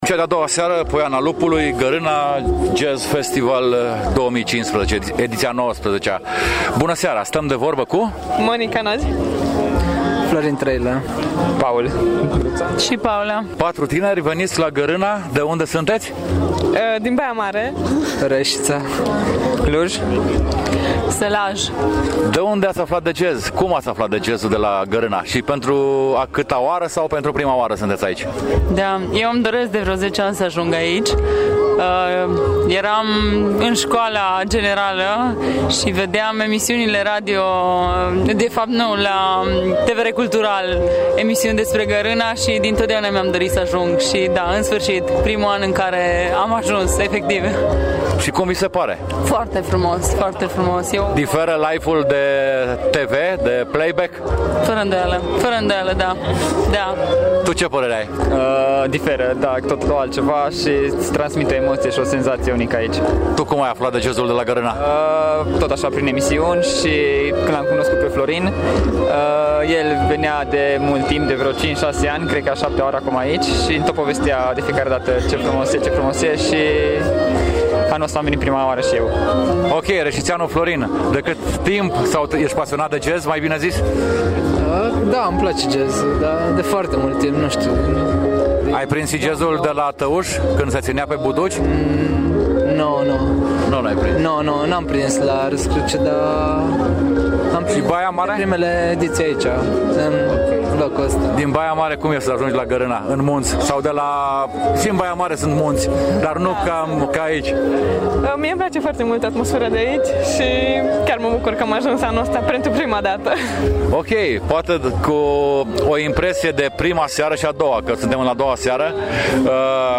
Tinerii veniţi din toată ţara, care au ales să petreacă sfârşitul de săptămână la Gărâna, s-au declarat încântaţi:
tineri-la-jazz.mp3